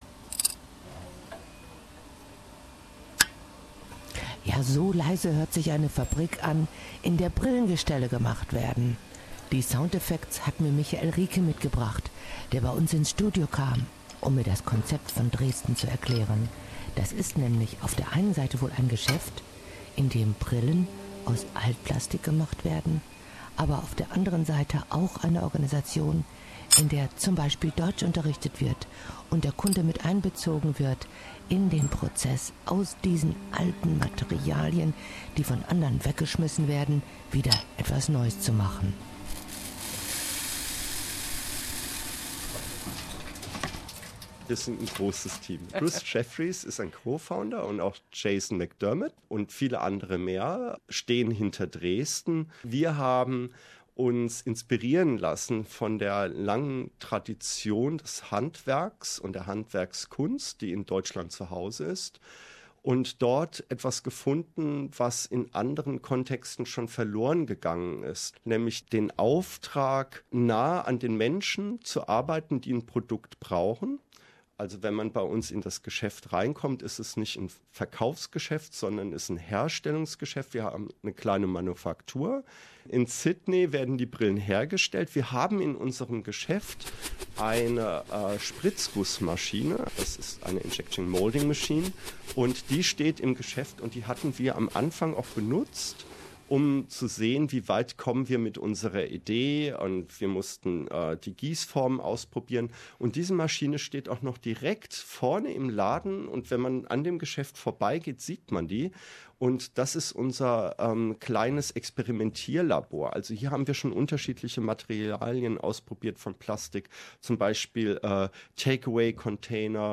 Pastor, Lehrer und Public Relations Spezialist, kam zu uns ins Studio, und brachte sogar ein paar Beispiele mit.